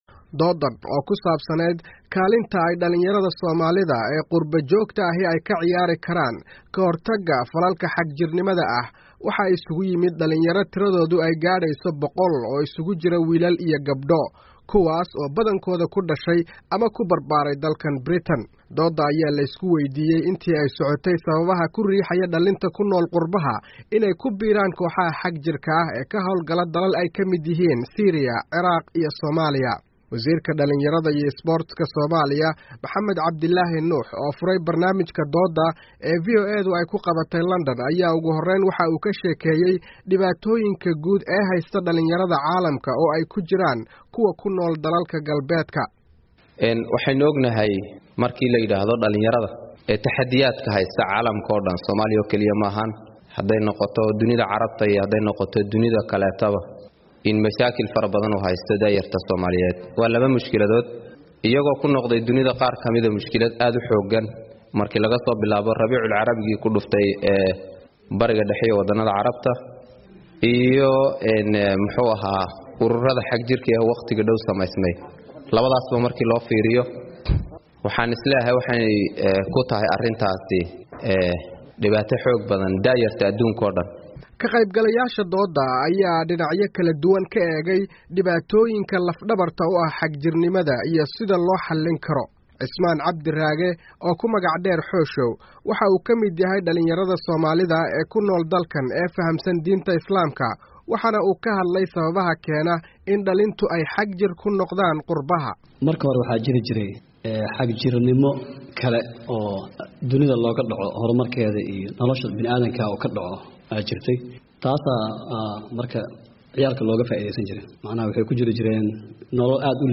VOA oo Kulan Dadweyne ku Qabatay London